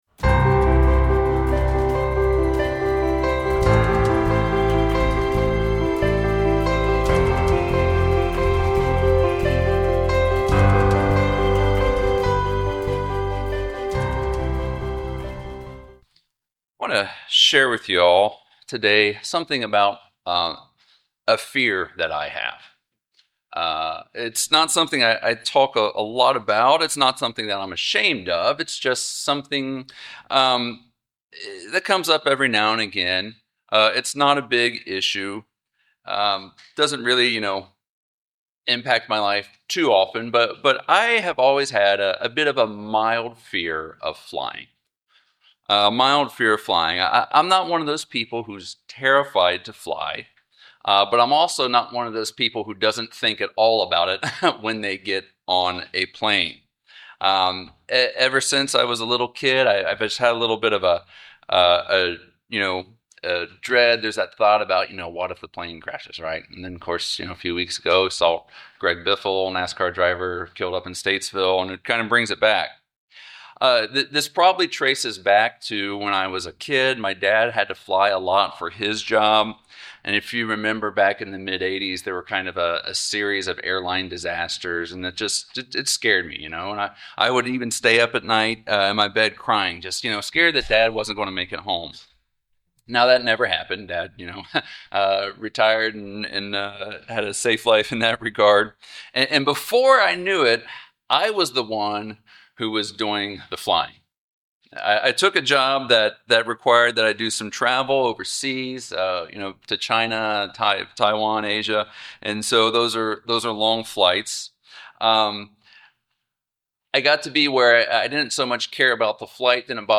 Note: At 46:51 to 46:59, there is an audio dropout.